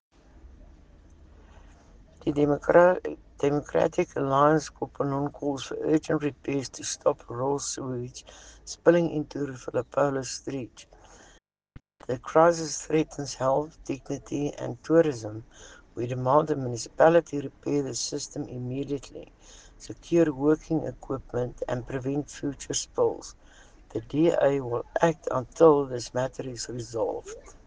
Afrikaans soundbites by Cllr Estelle Noordman and